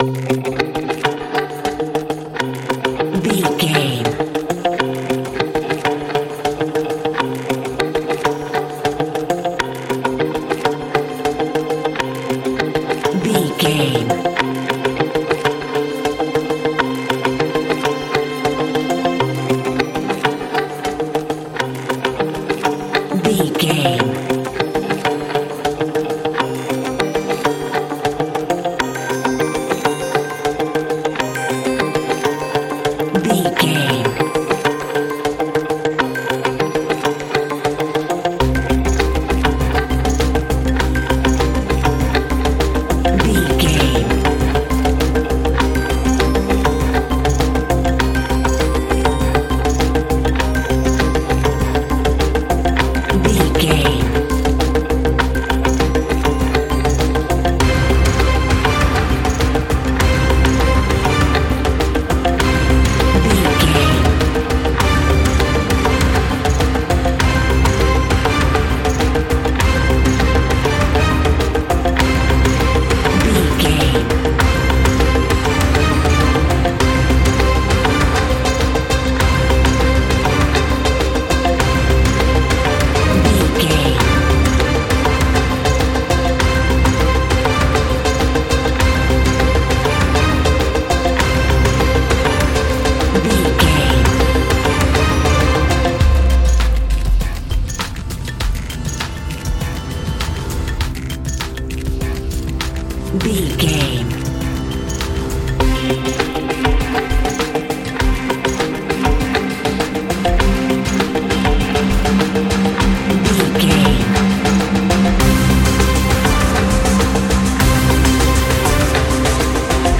Ionian/Major
C♭
electronic
dance
techno
trance
synths
synthwave
instrumentals